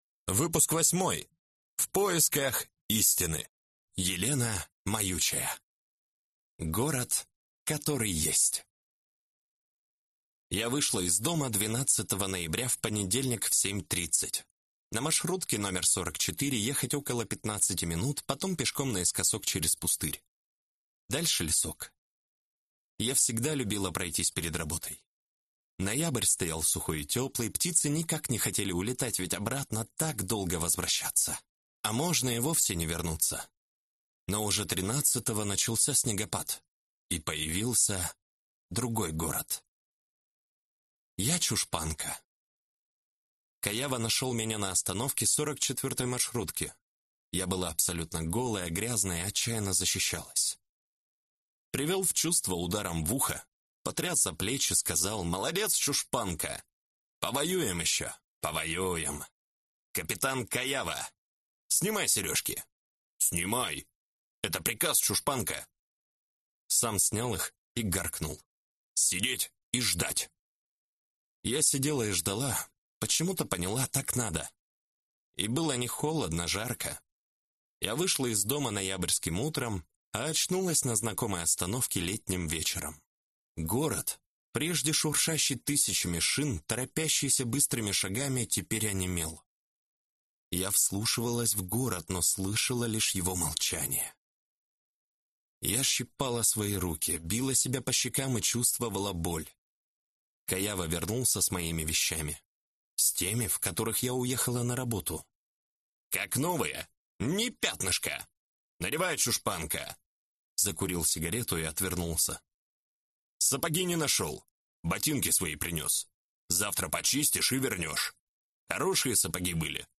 Аудиокнига Рассказы 8. В поисках истины | Библиотека аудиокниг